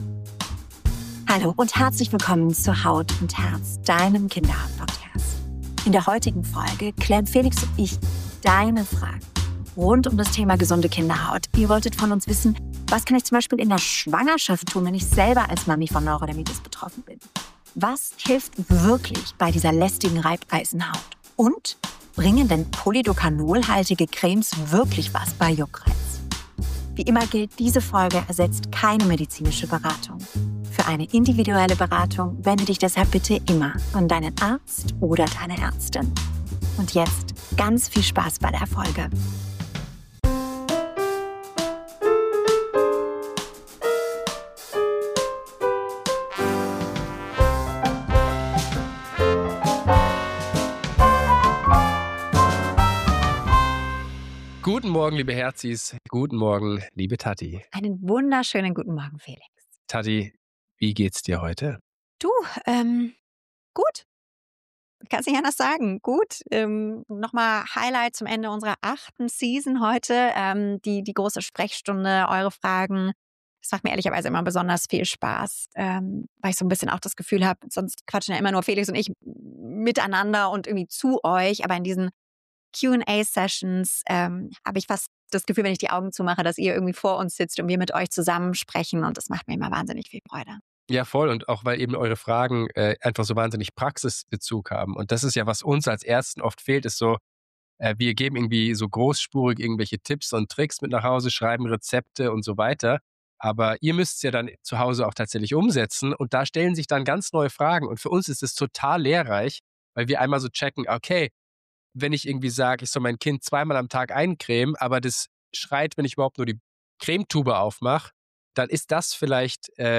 Ihr bekommt Orientierung, warum bestimmte Hautveränderungen auftreten können und worauf es für euch als Eltern wirklich ankommt. Locker, ehrlich und mit viel Verständnis für eure Sorgen.